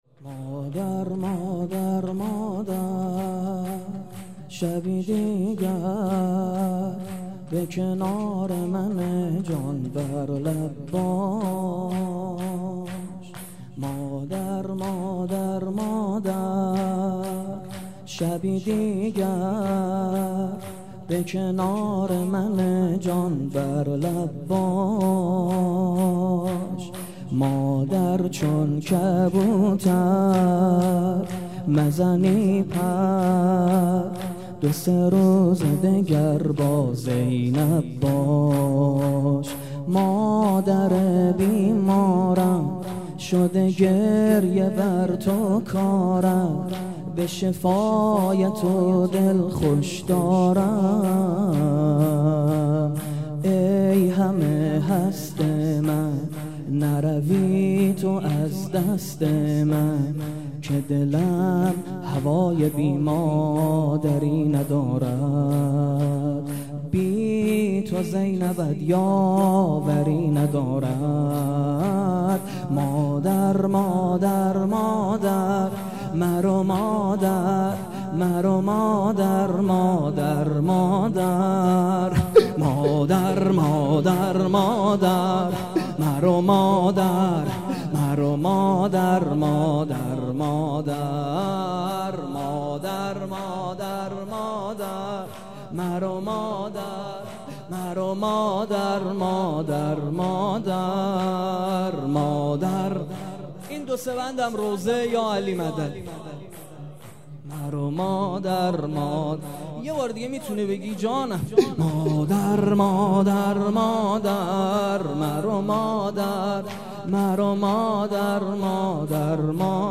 مطیع امر گزارش_صوتی مداحی شهادت حضرت فاطمه زهرا ( س ) فاطمیه 99(روایت75روز)